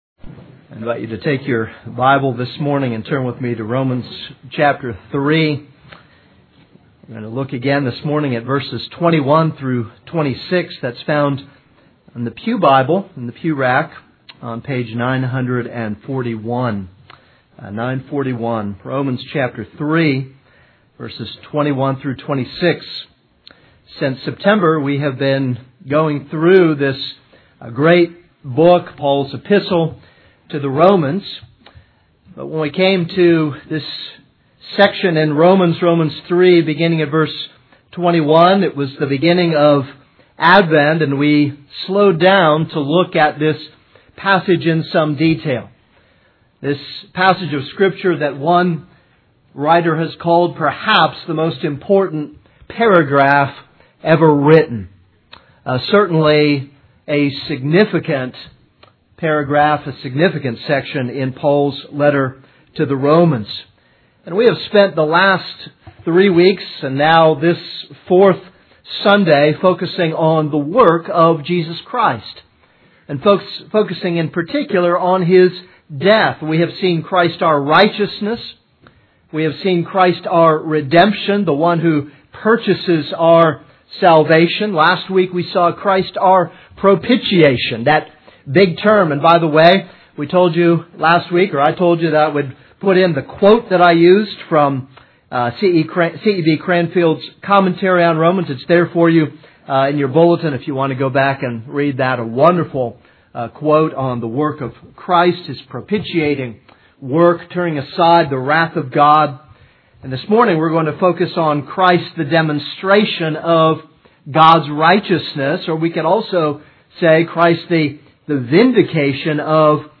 This is a sermon on Romans 3:21-26.